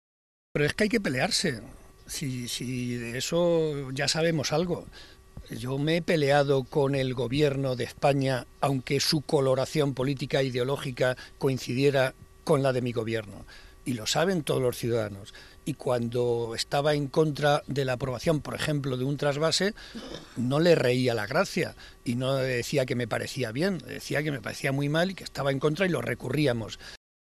José María Barreda, presidente del Grupo Parlamentario Socialista
Cortes de audio de la rueda de prensa